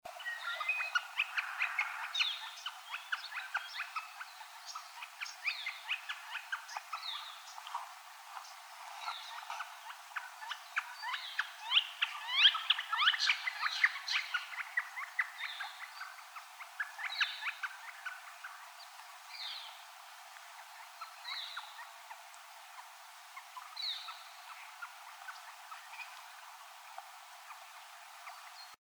Gilded Flicker
They have territorial drums and a "pee-er" call, but my favorite flicker call is the "wicka wicka." During this dance, two flickers (rivals or a pair) will face each other and swing their heads back and forth in a wild dance frenzy while calling "wicka wicka wicka!"